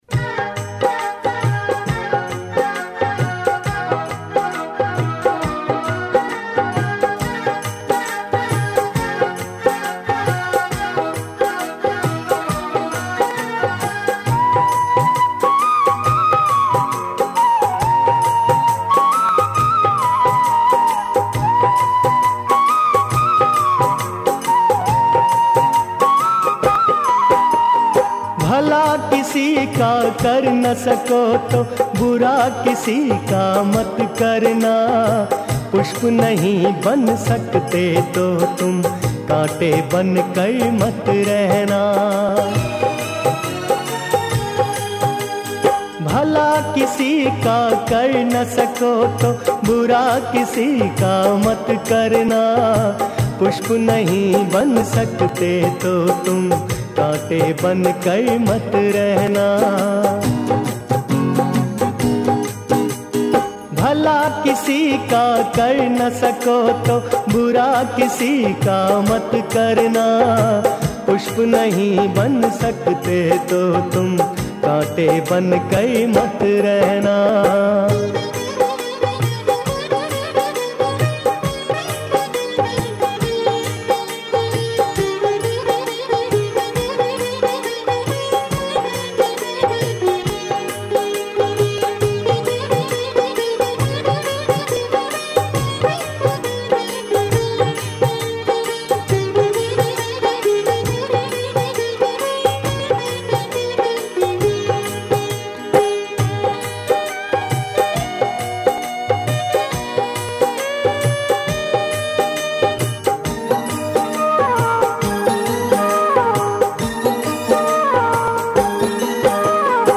भजन